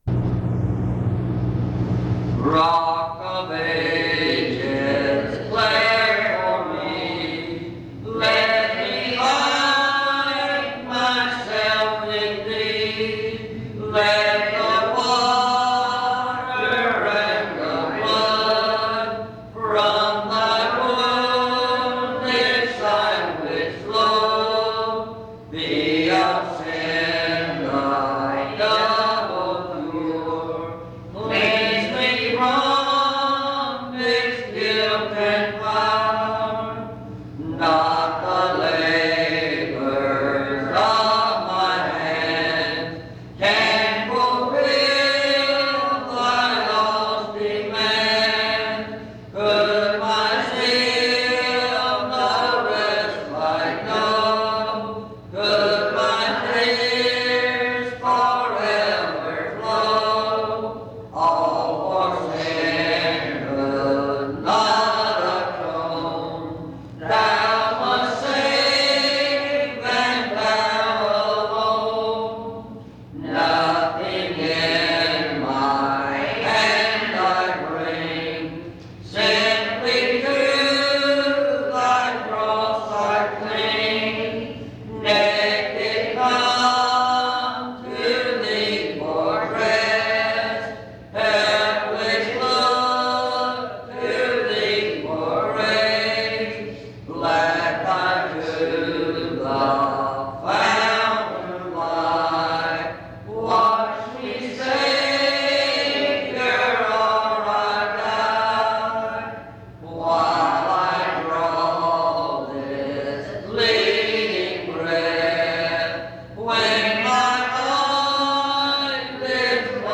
Dans Collection: Reidsville/Lindsey Street Primitive Baptist Church audio recordings La vignette Titre Date de téléchargement Visibilité actes PBHLA-ACC.001_046-B-01.wav 2026-02-12 Télécharger PBHLA-ACC.001_046-A-01.wav 2026-02-12 Télécharger